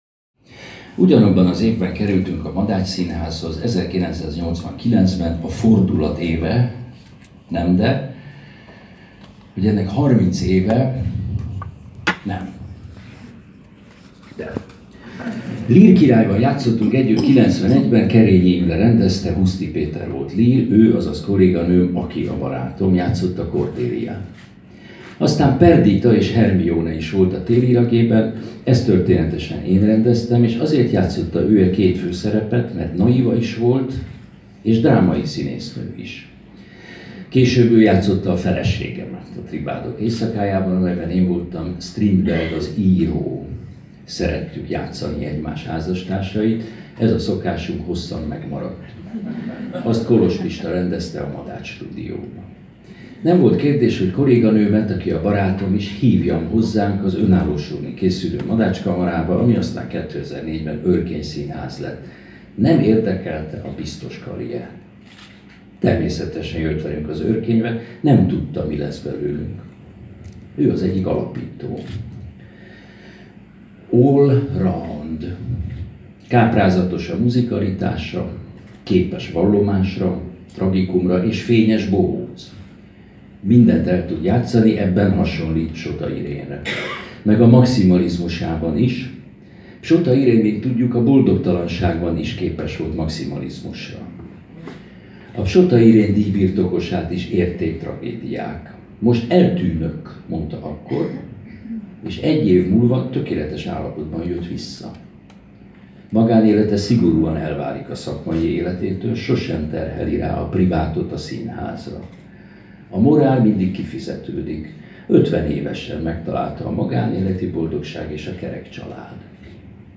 Laudációt mondott Mácsai Pál (a mellékelt hangfájlon), az Örkény Színház igazgatója, köszöntötte és a díjat átadta Hegedűs D. Géza.